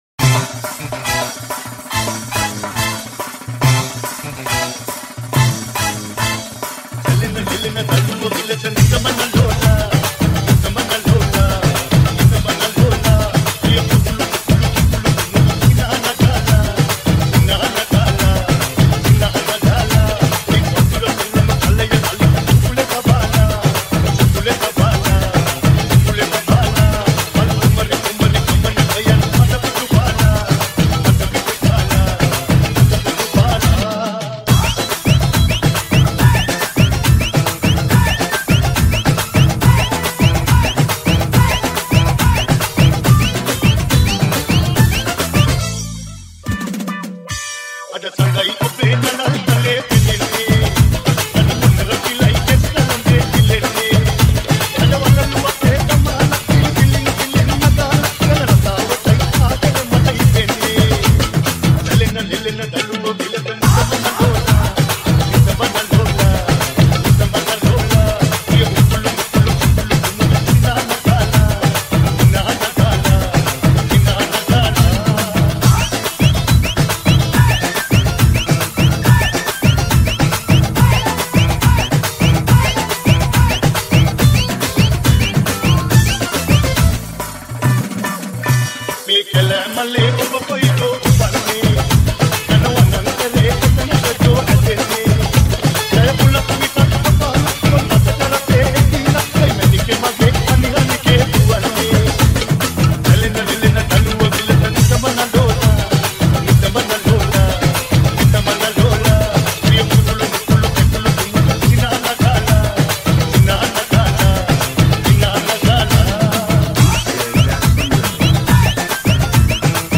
DJ SONGS REMIX SONGS